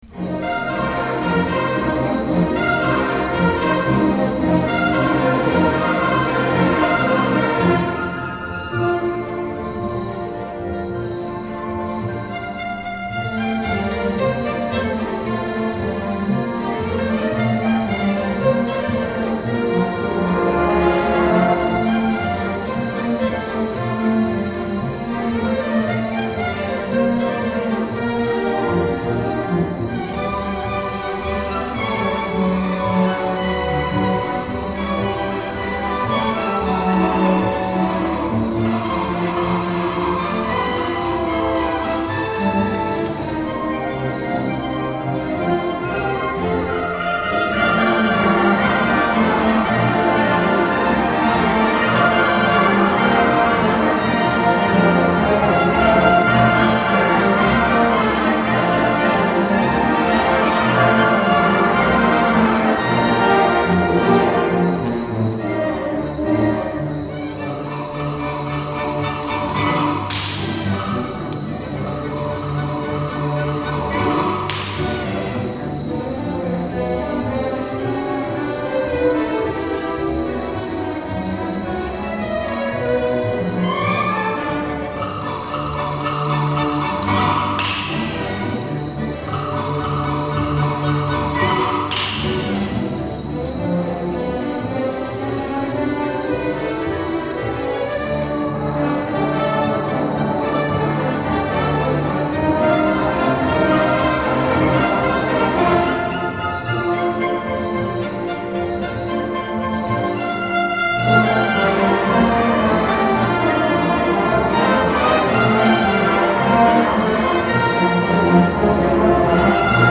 Album: Concert Noel 2013 (1er concert)